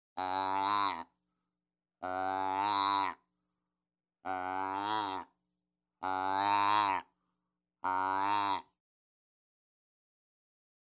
Il produit les sons authentiques du mâle, de la femelle et même du faon.
Femelle